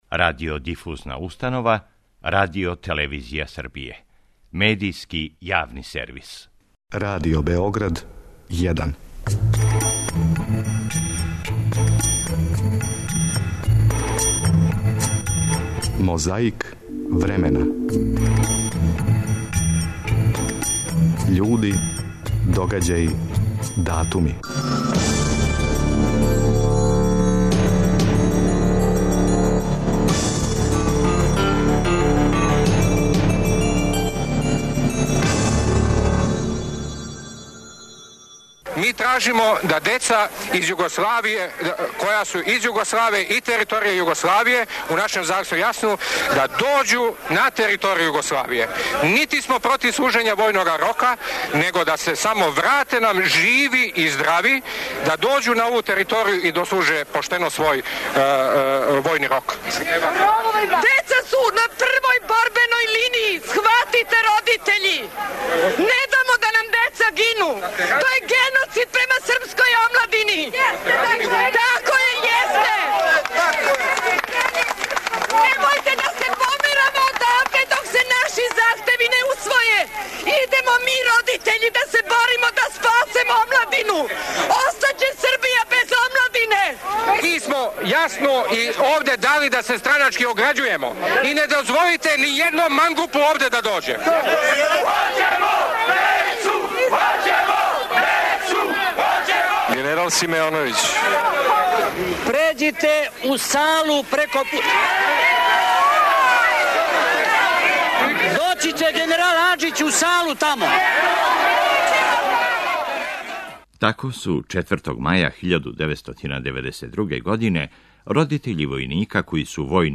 Мозаик времена - борба против пилећег памћења, емисија Првог програма Радио Београда која је почела са радом октобра 2001. године.